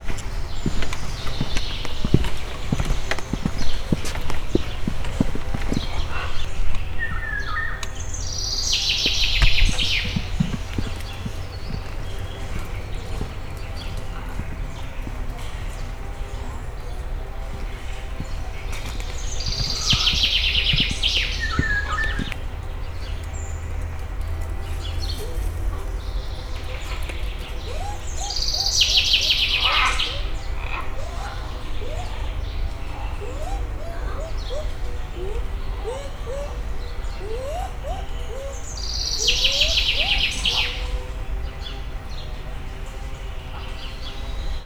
Directory Listing of /_MP3/allathangok/szegedizoo2011_standardt/erdeikutya/